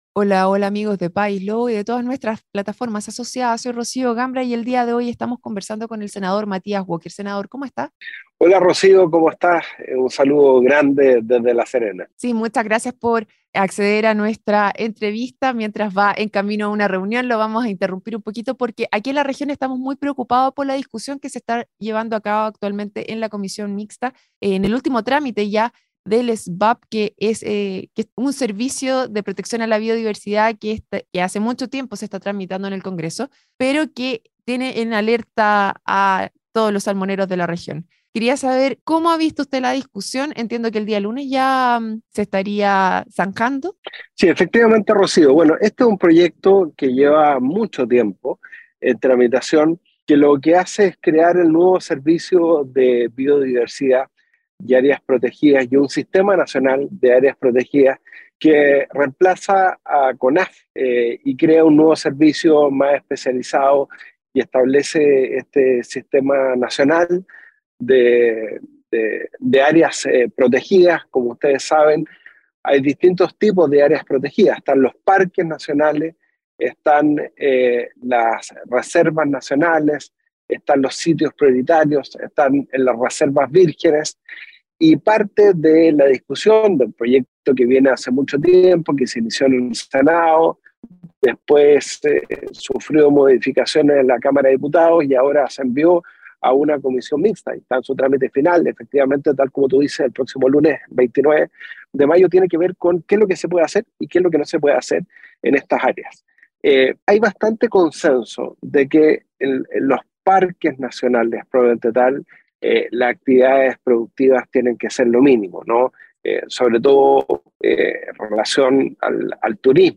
Durante la entrevista, se trató el tema de la posible continuidad de la producción de salmoneras en reservas nacionales.